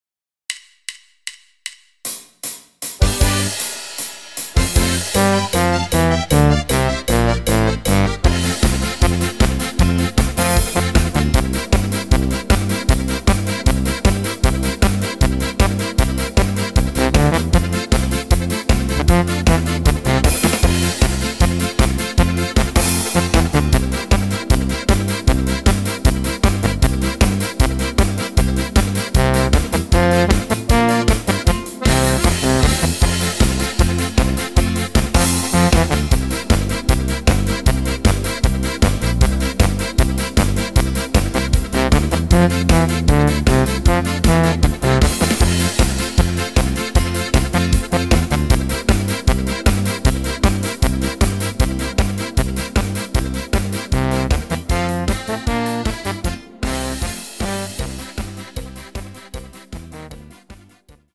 fetziges Akkordeon Instrumental